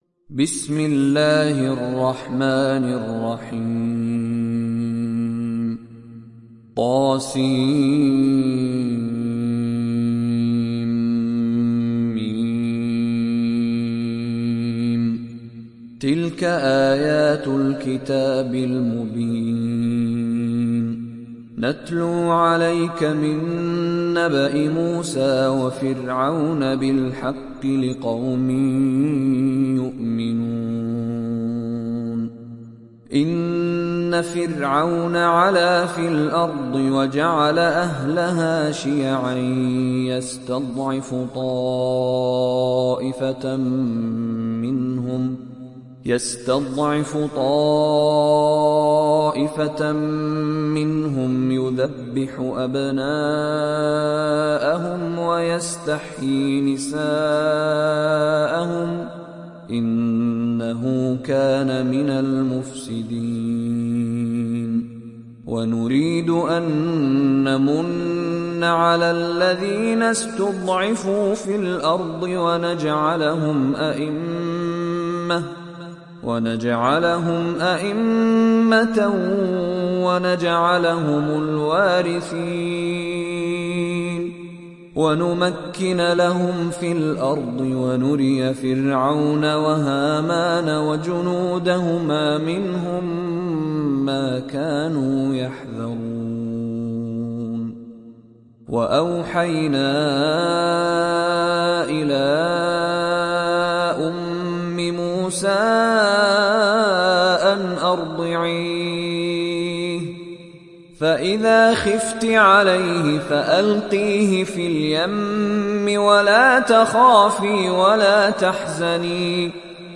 Surat Al Qasas Download mp3 Mishary Rashid Alafasy Riwayat Hafs dari Asim, Download Quran dan mendengarkan mp3 tautan langsung penuh